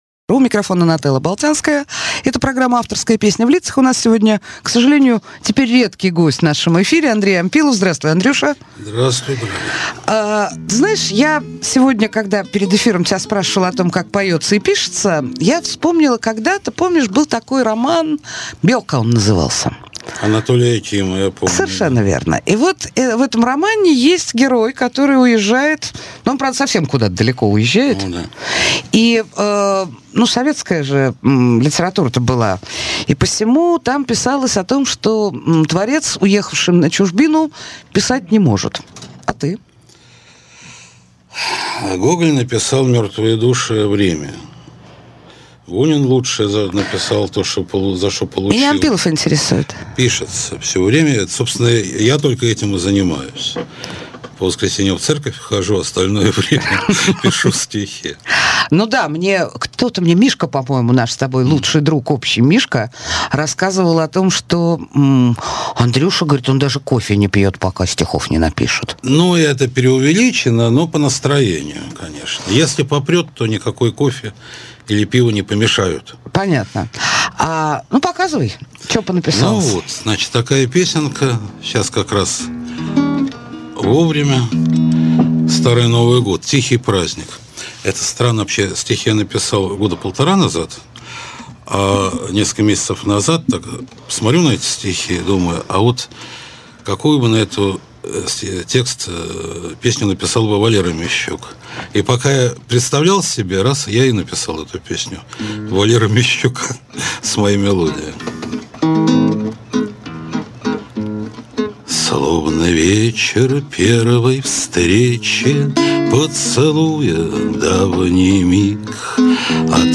Жанр: Авторская песня